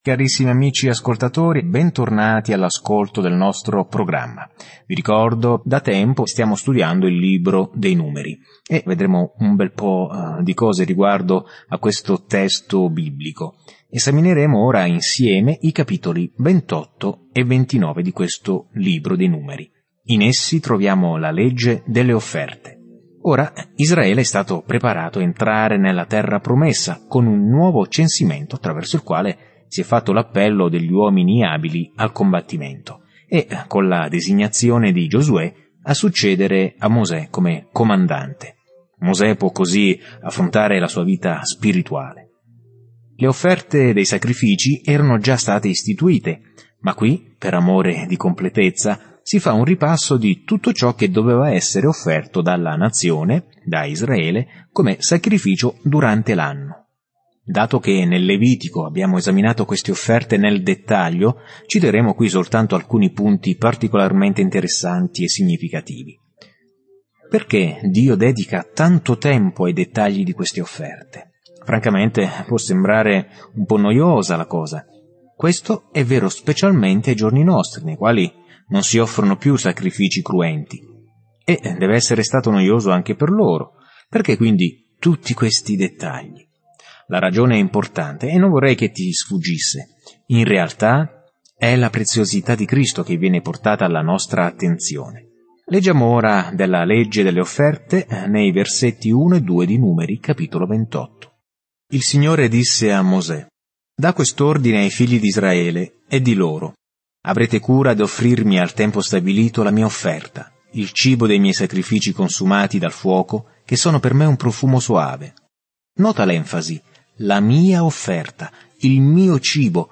Scrittura Numeri 28 Numeri 29 Numeri 30 Giorno 17 Inizia questo Piano Giorno 19 Riguardo questo Piano Nel libro dei Numeri, camminiamo, vaghiamo e adoriamo con Israele durante i 40 anni nel deserto. Viaggia ogni giorno attraverso Numeri mentre ascolti lo studio audio e leggi versetti selezionati della parola di Dio.